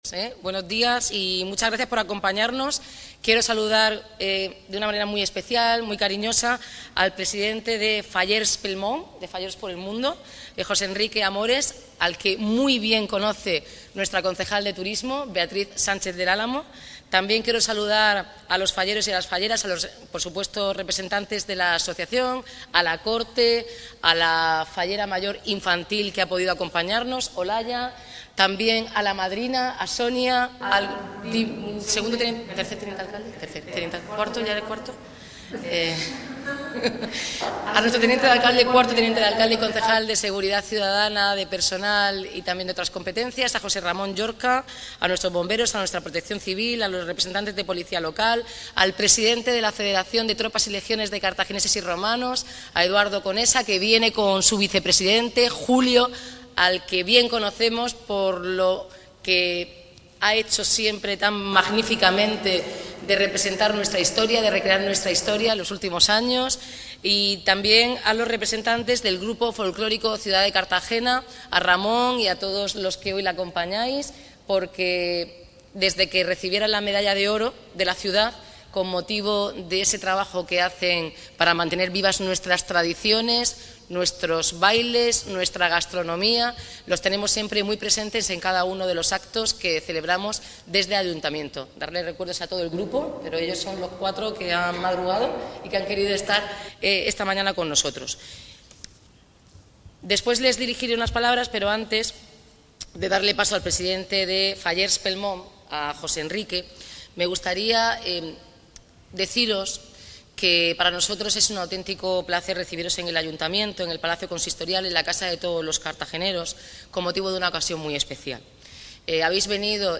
Una numerosa comitiva de estas fiestas representada por la asociación Fallers Pel Món ha sido recibida por la alcaldesa Noelia Arroyo en el Palacio Consistorial este sábado, 11 de enero. Se ha celebrado un acto de agradecimiento en el que han hecho entrega de placas al Consistorio, Policía Local, Bomberos, Protección Civil y las fiestas históricas de Carthagineses y Romanos.